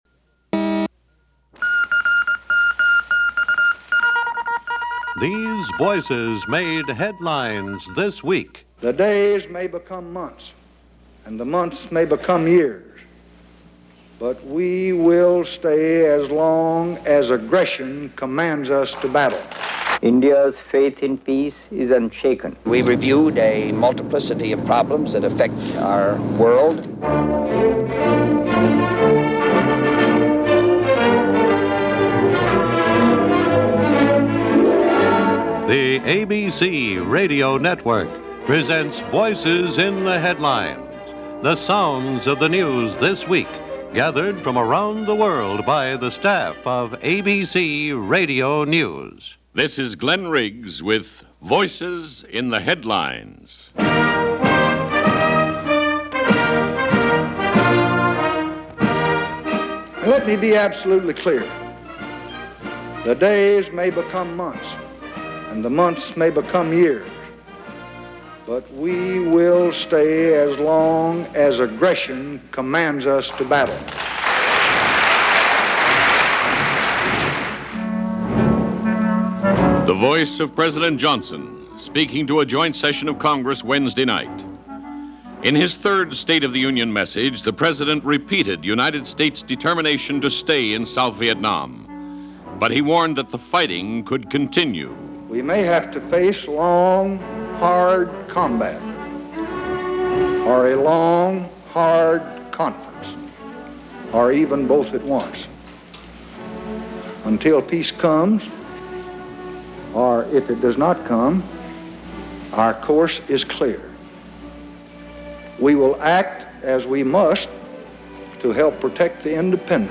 – ABC Radio – Voices In The Headlines – January 16, 1966 –